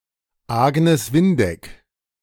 Agnes Windeck (German: [ˈaɡnɛs ˈvɪnˌdɛk]
De-Agnes_Windeck.ogg.mp3